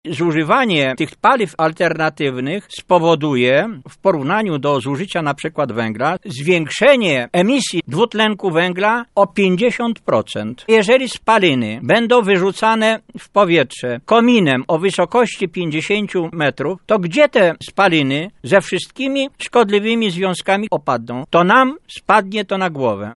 Każde źródło wytwarzania spalin jest niebezpieczne dla zdrowia, tym bardziej w centrum miasta – mówi przewodniczący rady dzielnicy Tatary, Józef Nowomiejski.